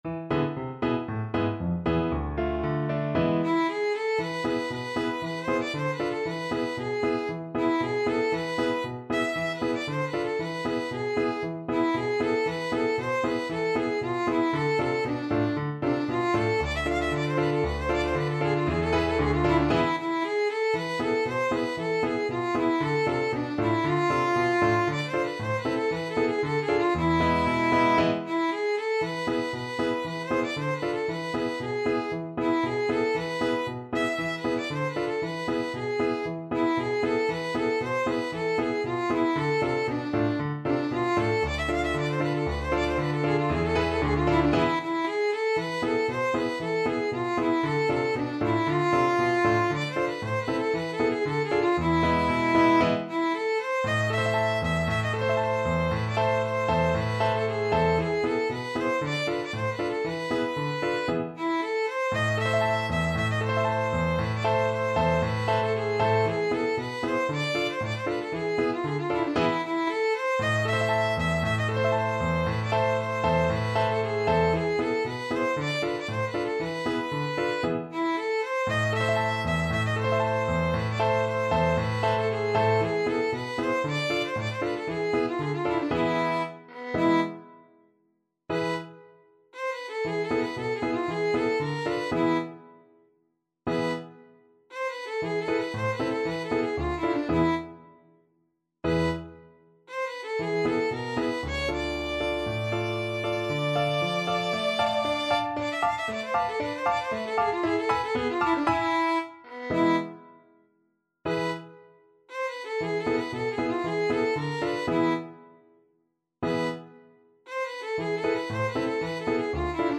Traditional Trad. Der Heyser Bulgar (Klezmer) Violin version
A minor (Sounding Pitch) (View more A minor Music for Violin )
Allegro =c.116 (View more music marked Allegro)
2/4 (View more 2/4 Music)
Traditional (View more Traditional Violin Music)